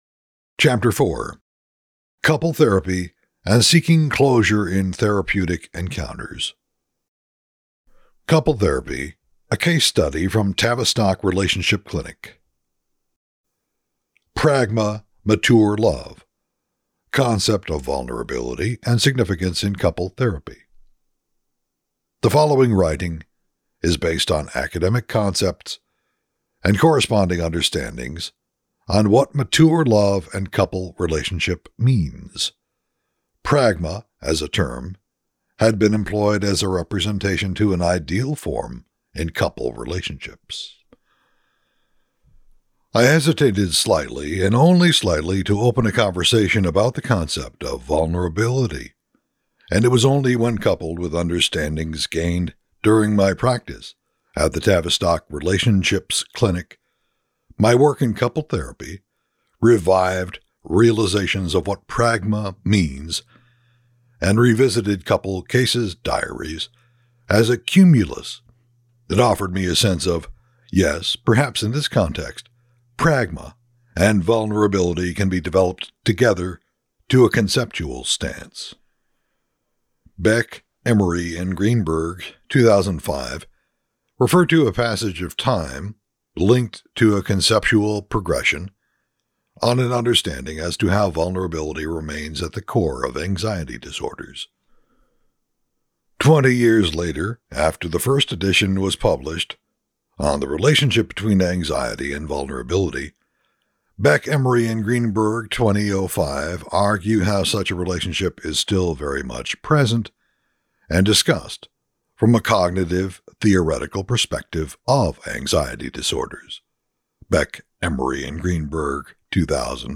Sample Introduction from audiobook of strategies en psychotherapie in French - Introduction Chapitre